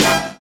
HIT XBAND 00.wav